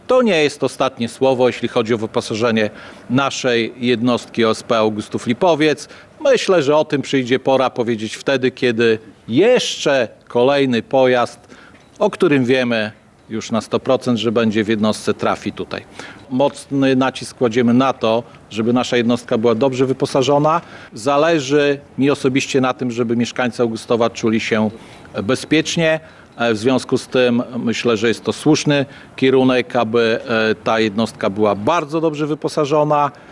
Uroczyste przekazanie wozu odbyło się w piątek (21.03) przed Urzędem Miasta w Augustowie.
Obecny na miejscu Mirosław Karolczuk, burmistrz Augustowa, podkreślił, że dzięki nowoczesnemu wyposażeniu jest to jeden z najlepszych wozów pożarniczych w województwie.
Burmistrz-.mp3